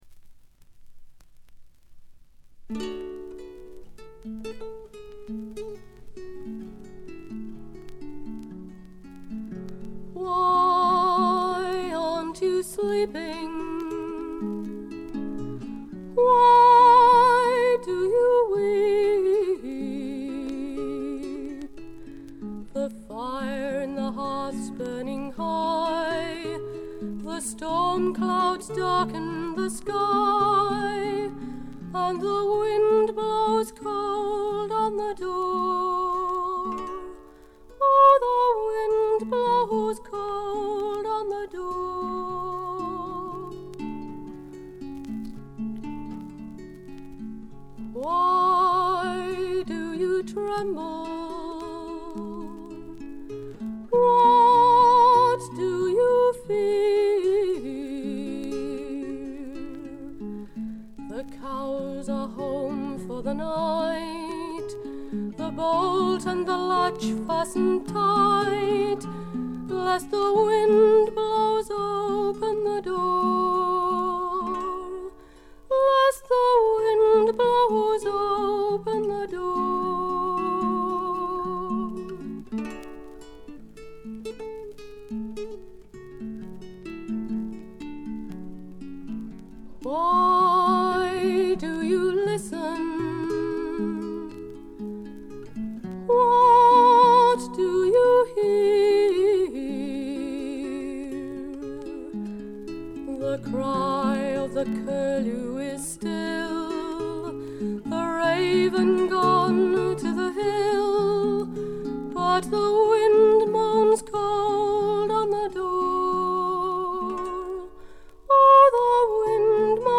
軽微なバックグラウンドノイズ、チリプチ。
試聴曲は現品からの取り込み音源です。